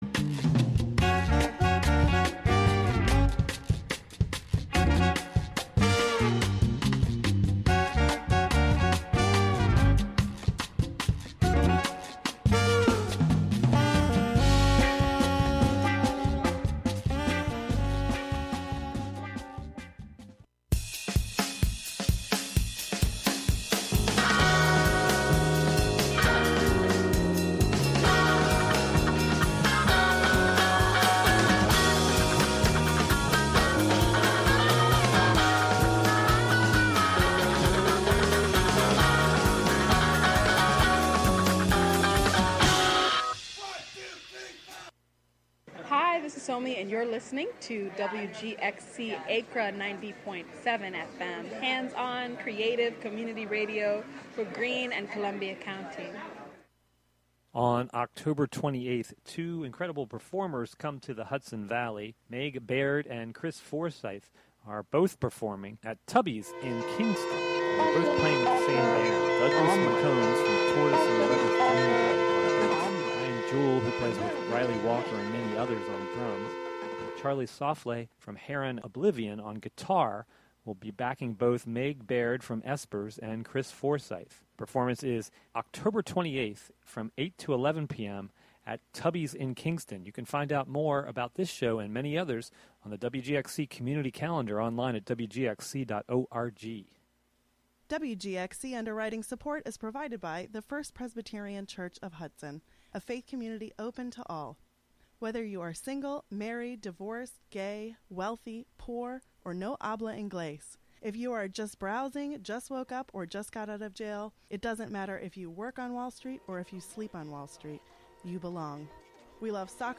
On this show you will hear songs and sounds from a variety of genres as well as from unclassifiable styles of music and experimentation. The show will sometimes feature live performances from near and far and periodically unpredictable guests will join to share music.